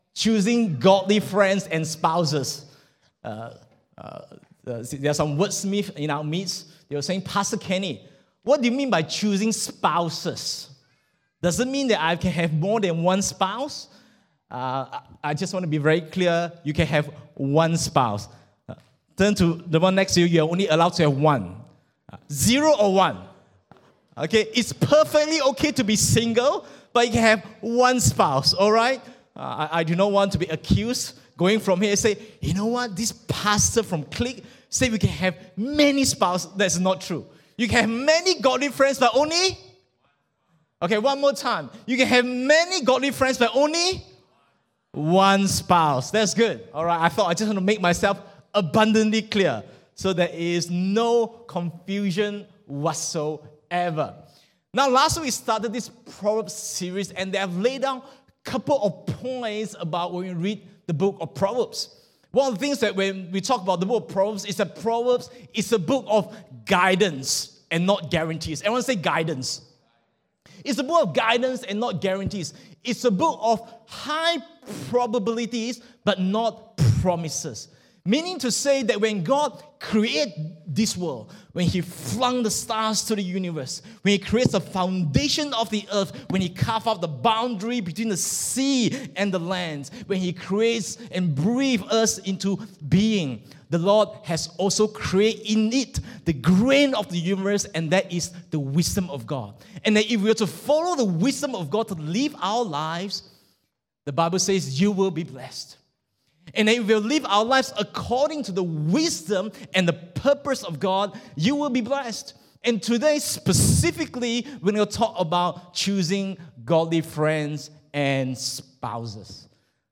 English Worship Service - 07th May 2023
Sermon Notes